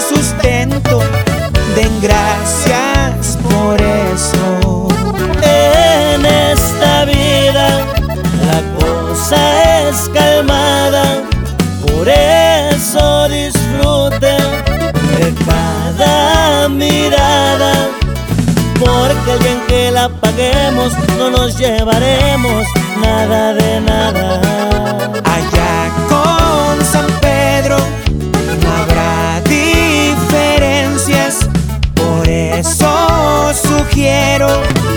Música Mexicana Latin